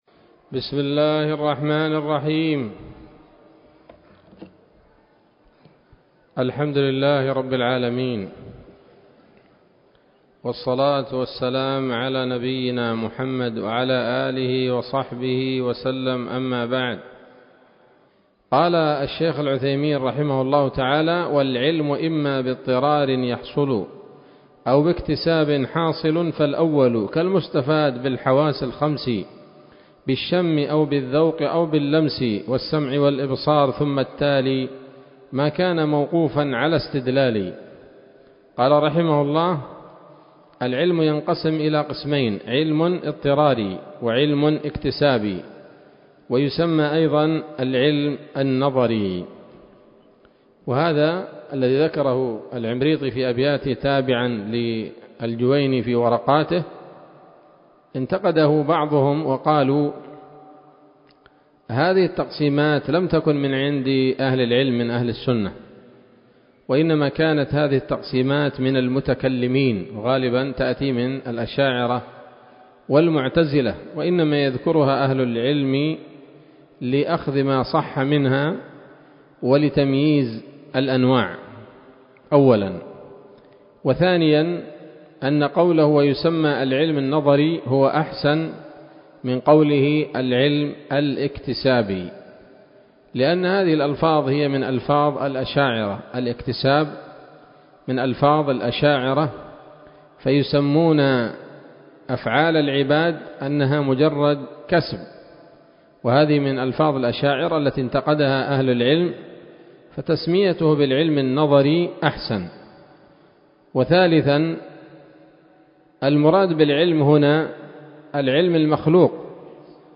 الدرس الرابع والعشرون من شرح نظم الورقات للعلامة العثيمين رحمه الله تعالى